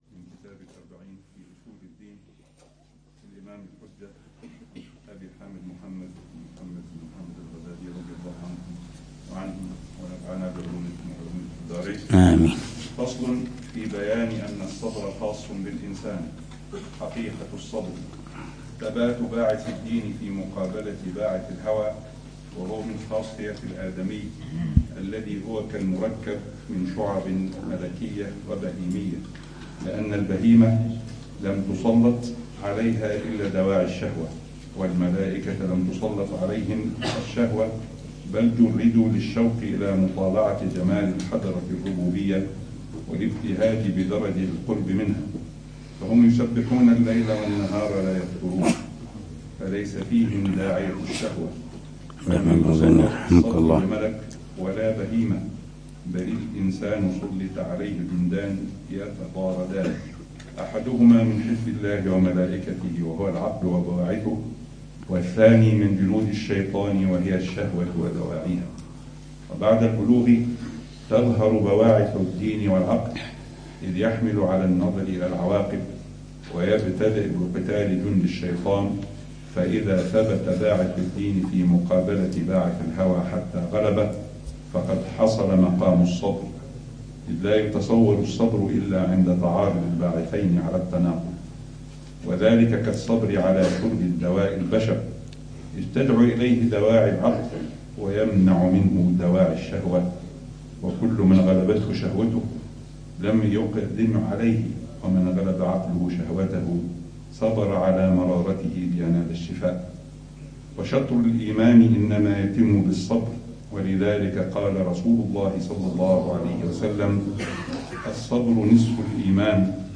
الدرس ال35 في شرح الأربعين في أصول الدين: يستكمل معالم الصبر بوصفه نصف الإيمان، ثم الشكر؛ من تعريفه المجمل إلى صورته الباطنة.